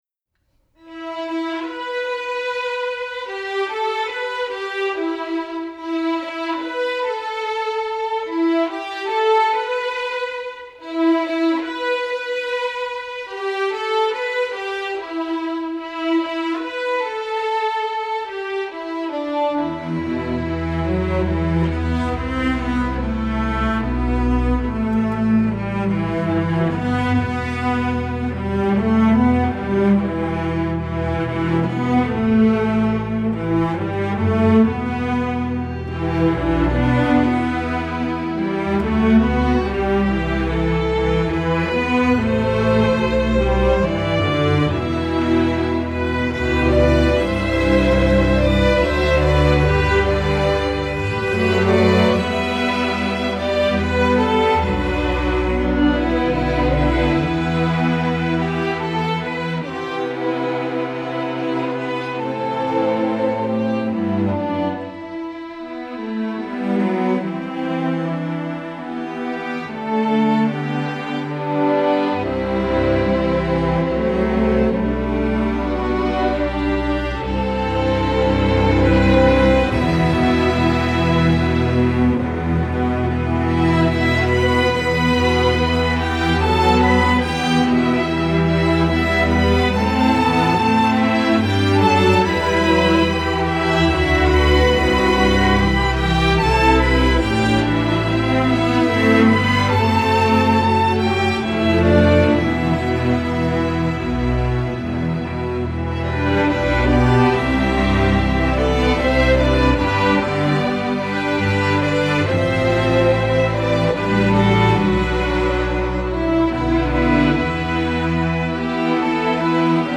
Instrumentation: string orchestra
folk, sacred, spiritual